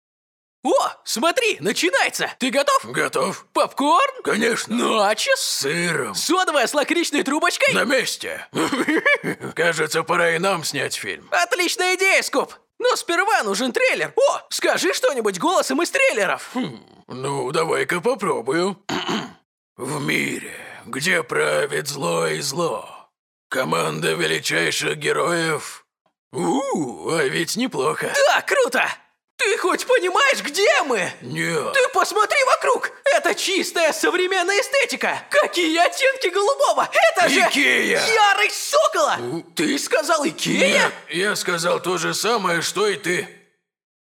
Гибкая подача. Голос средний - баритон, высокий - тенор, молодежный, взрослый.
audio technica at2020 / scarlett solo 2gen
Демо-запись №2 Скачать